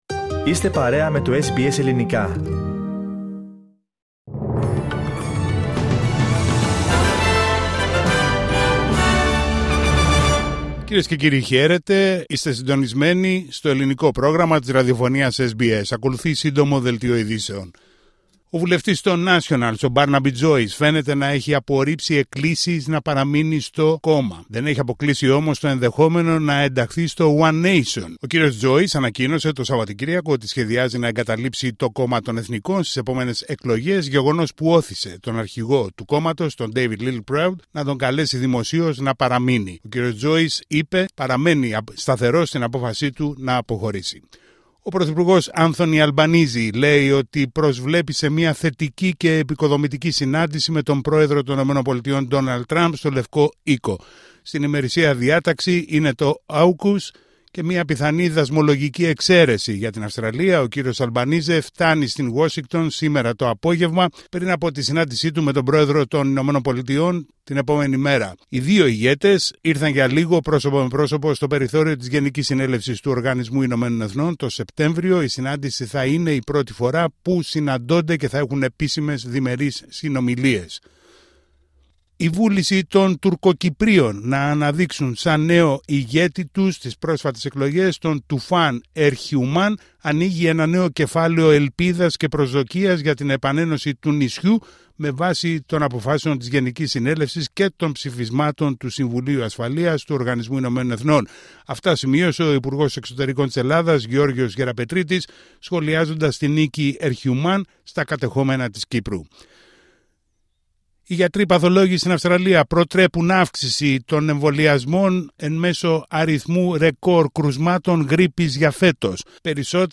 Ειδήσεις στα Ελληνικά από την Αυστραλία την Ελλάδα την Κύπρο και όλο τον κόσμο